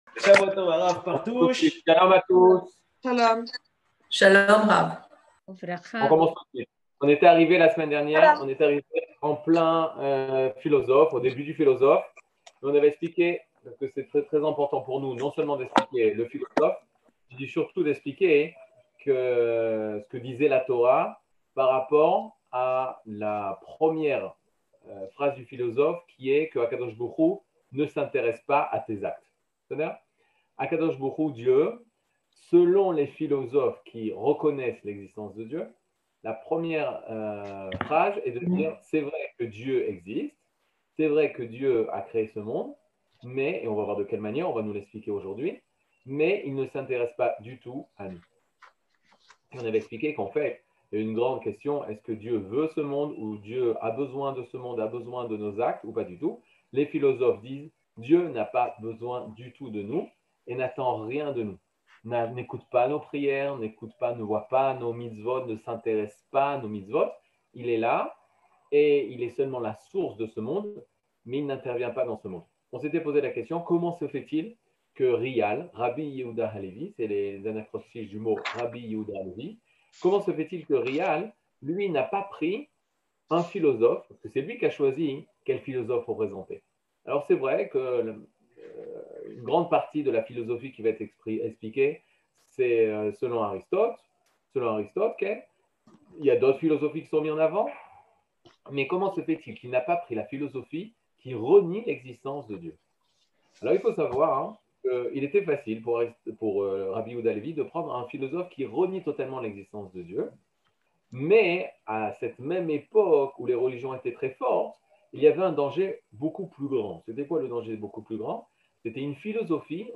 Catégorie Le livre du Kuzari partie 4 00:58:49 Le livre du Kuzari partie 4 cours du 16 mai 2022 58MIN Télécharger AUDIO MP3 (53.84 Mo) Télécharger VIDEO MP4 (86.9 Mo) TAGS : Mini-cours Voir aussi ?